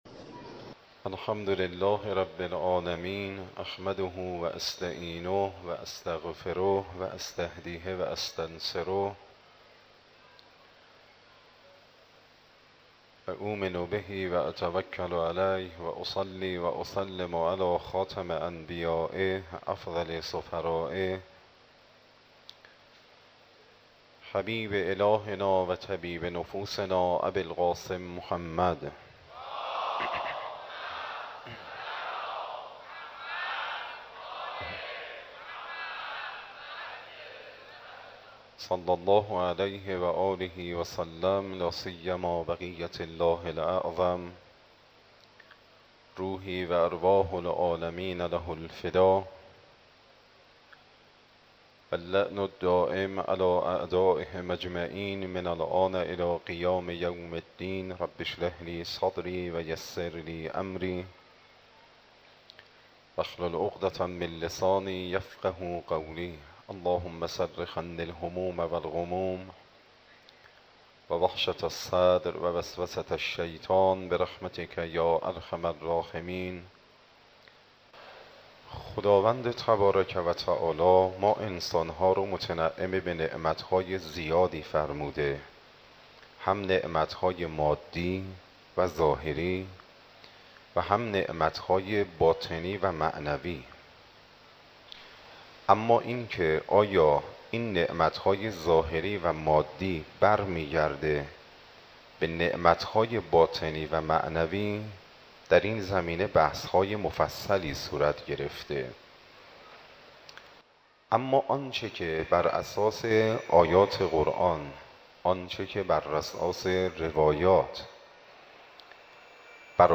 Moharrame 93, Shabe 01, Sokhanrani.mp3